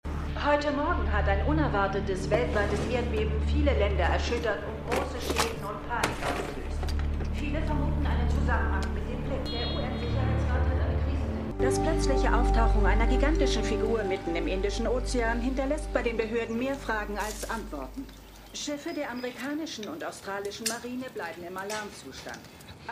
Eternals__Nachrichtensprecherin.mp3